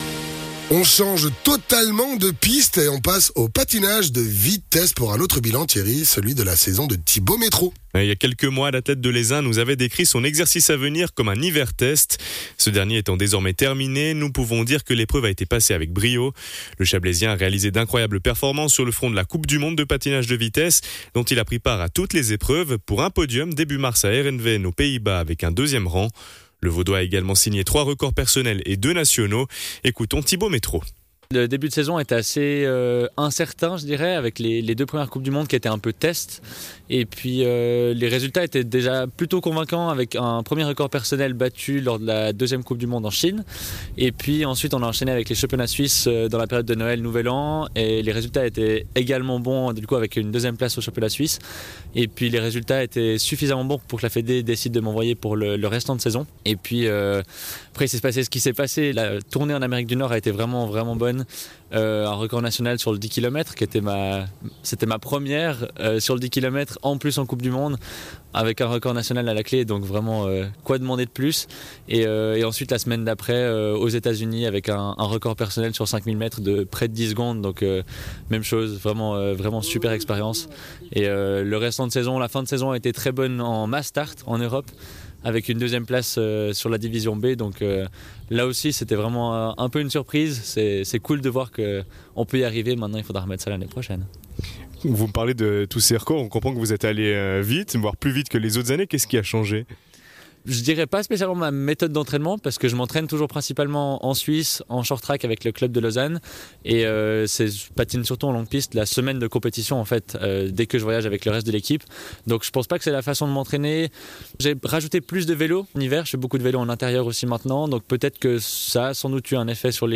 patineur